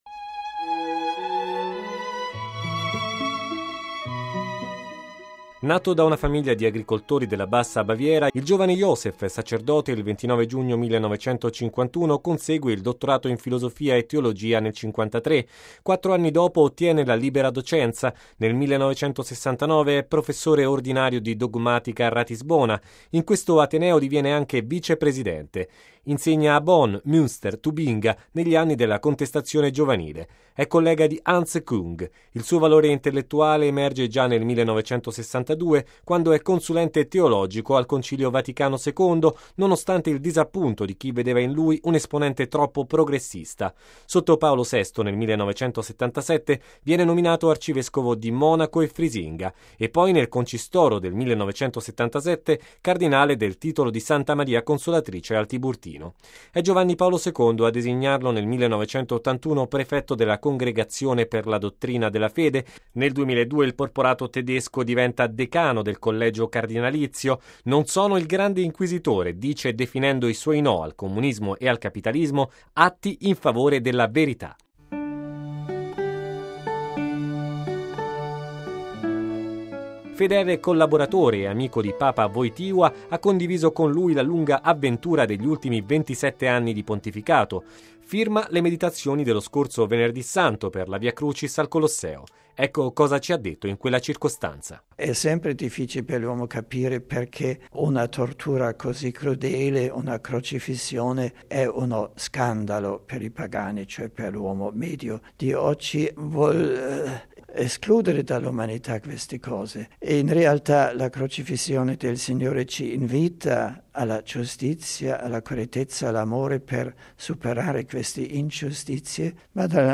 Dalla Baviera al soglio di Pietro Home Archivio 2005-04-19 21:37:54 Dalla Baviera al soglio di Pietro Chi è Papa Benedetto XVI? Una biografia, nel servizio